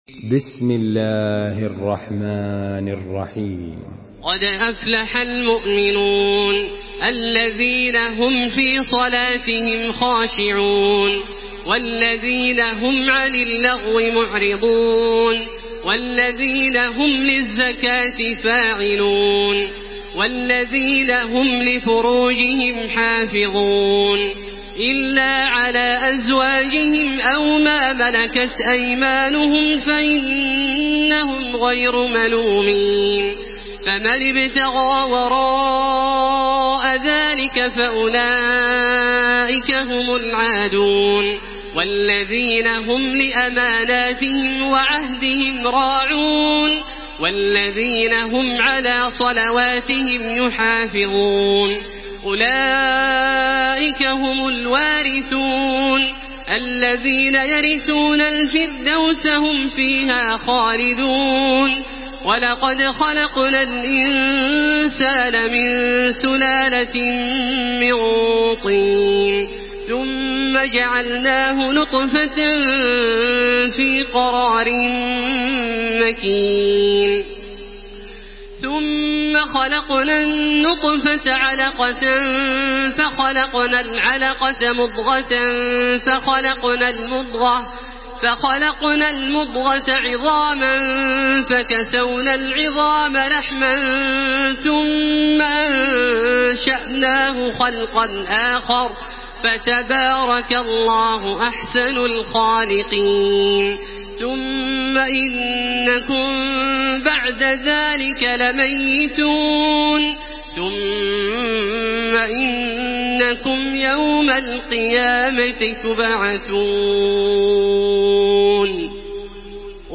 Makkah Taraweeh 1433
Murattal Hafs An Asim